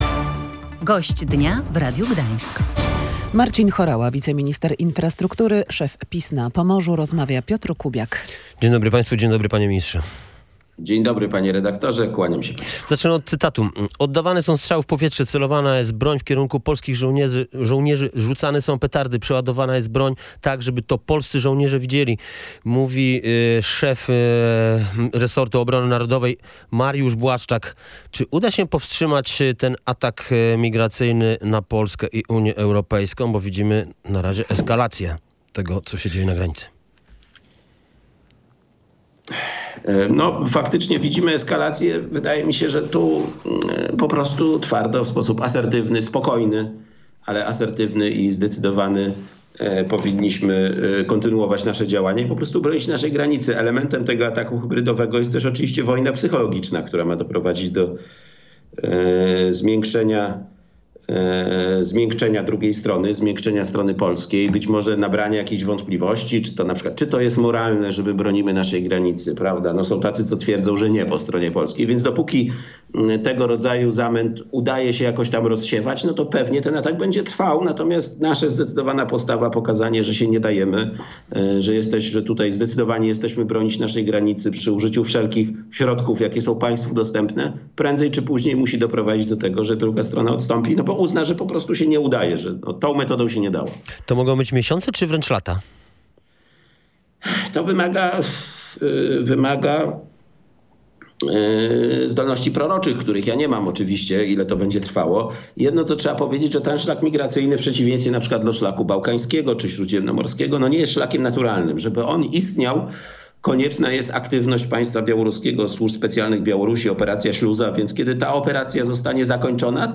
Sytuacja na granicy polsko-białoruskiej, a także decyzje instytucji europejskich w sprawach naszego kraju zdominowały rozmowę